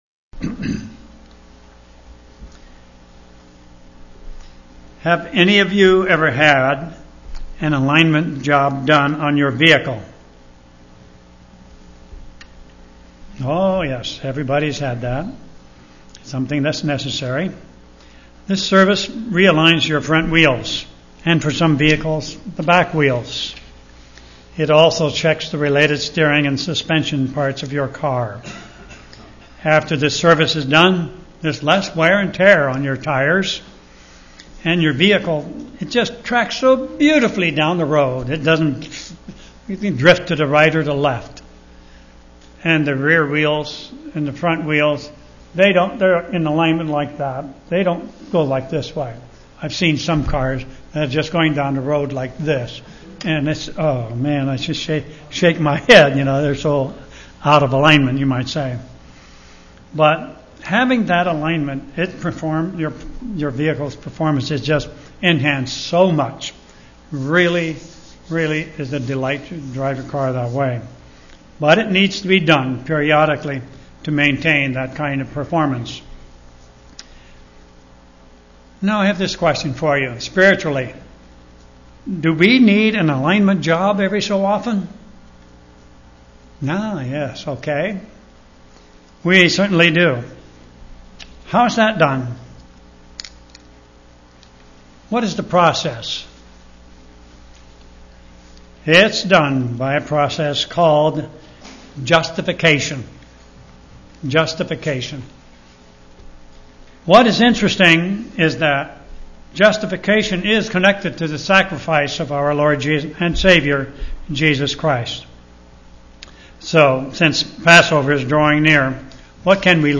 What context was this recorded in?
Given in Olympia, WA